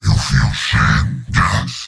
Giant Robot lines from MvM. This is an audio clip from the game Team Fortress 2 .